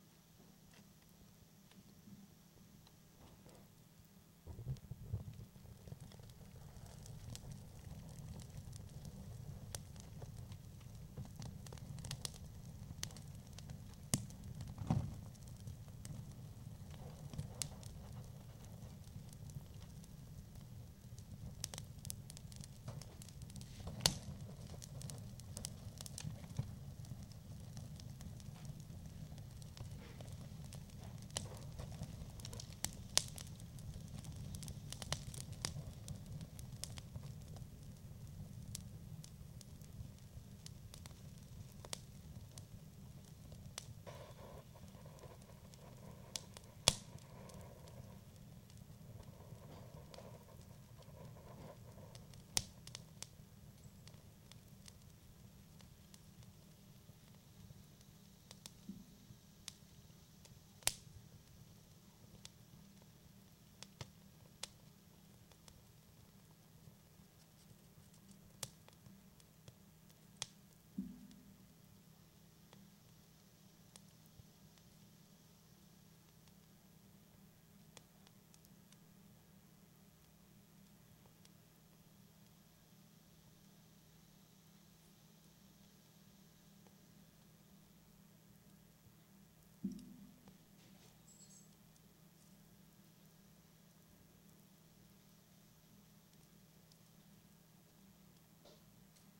篝火噼啪声 " 篝火噼啪声3
描述：更多的是营火噼啪作响的声音。
标签： 噼啪声 篝火 木材 微声 燃烧的
声道立体声